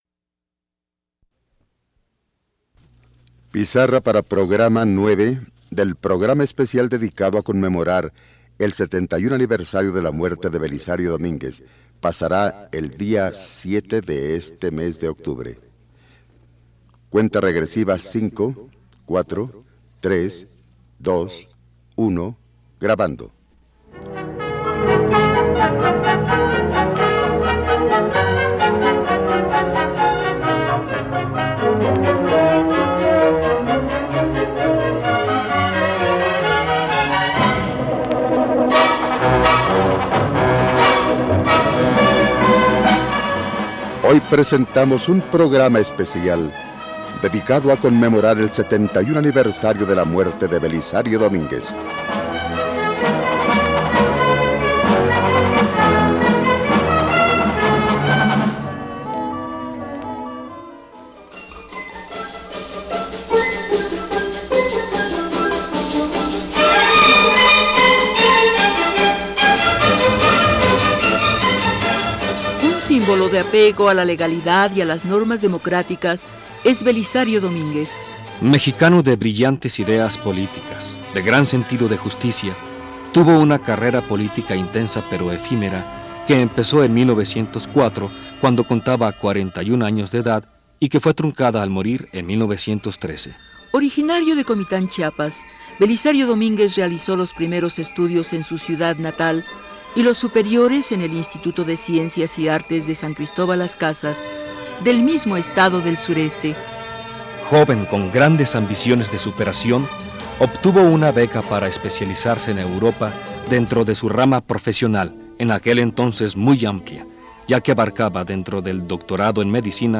Escucha una emisión especial sobre Belisario Domínguez en el programa “Memorias de una nación”, transmitido el 7 de octubre de 1984.